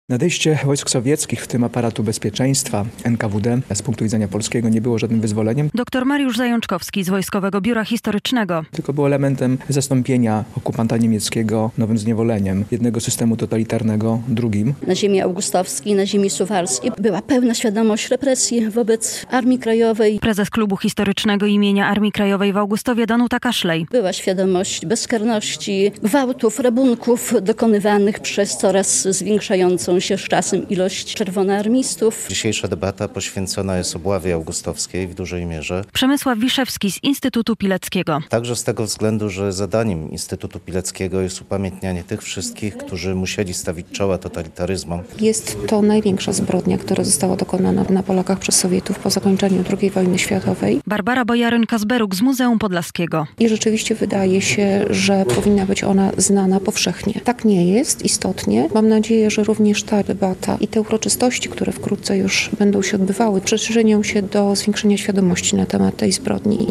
O "prawdziwym końcu" drugiej wojny światowej mówiono w piątek (9.05) podczas debaty naukowej w augustowskiej siedzibie Instytutu Pileckiego.
Debata o Obławie Augustowskiej - relacja